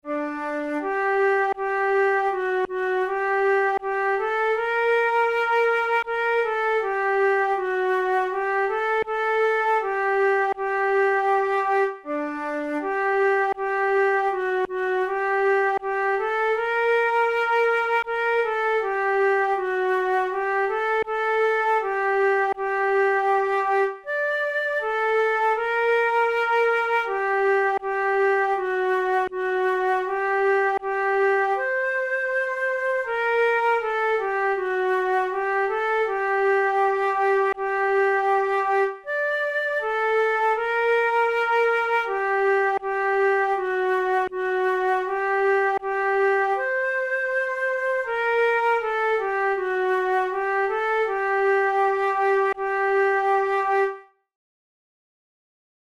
Traditional Norwegian lullaby